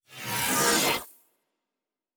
pgs/Assets/Audio/Sci-Fi Sounds/Doors and Portals/Teleport 2_1.wav at 7452e70b8c5ad2f7daae623e1a952eb18c9caab4
Teleport 2_1.wav